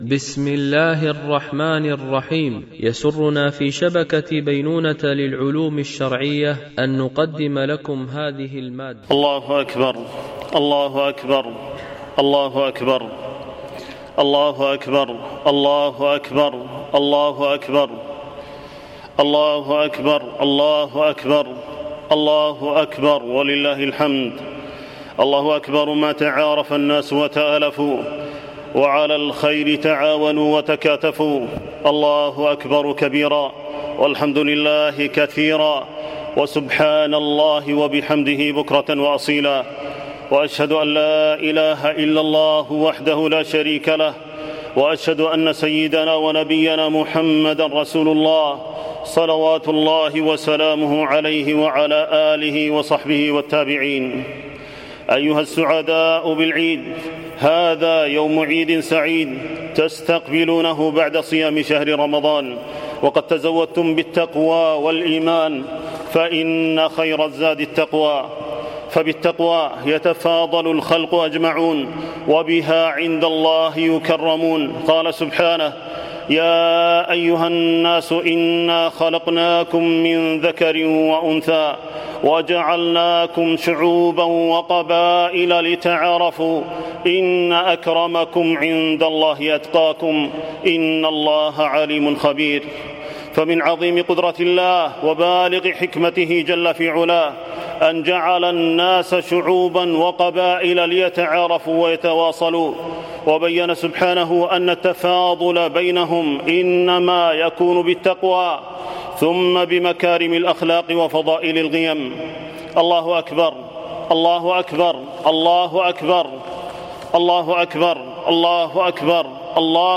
خطبة عيد الفطر لعام 1443 هـ